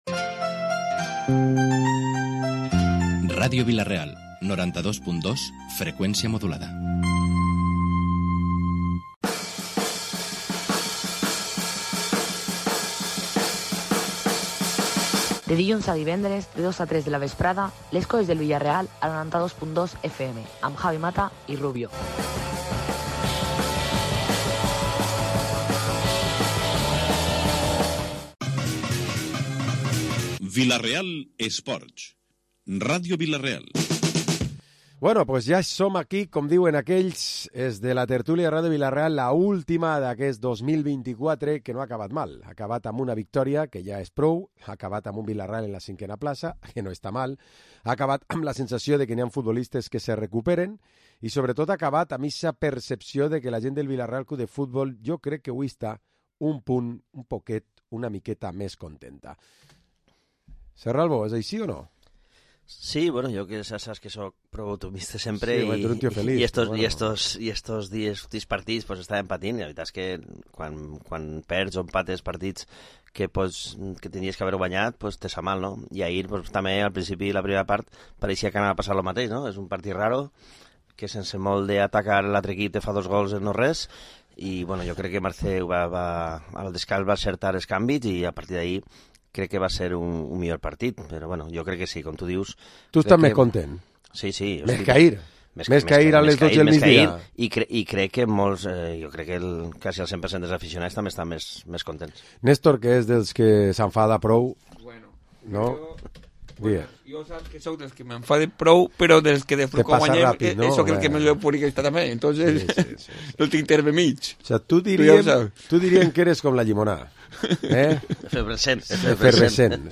Programa Esports dilluns tertúlia 23 de desembre